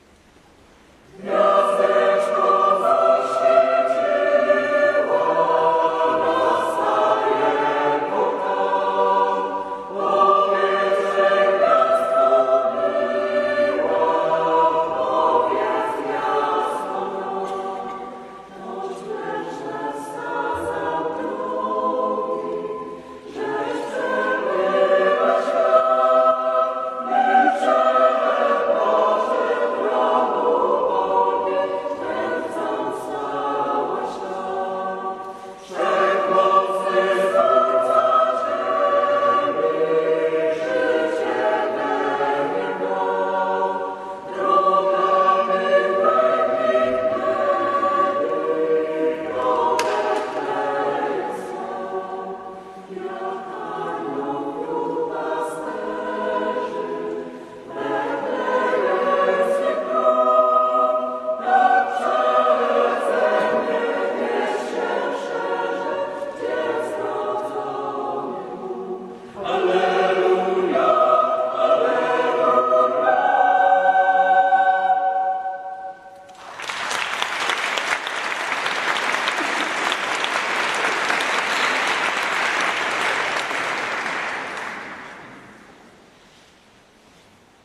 Chór parafii Dobrego Pasterza – Lubelski Festiwal Chórów Parafialnych
Czterogłosowy chór parafialny tworzą pasjonaci wspólnego śpiewania. Zespół łączy pokolenia, a w poszczególnych sekcjach jest miejsce i dla uczącej się młodzieży, i zapracowanych dorosłych, i aktywnych seniorów.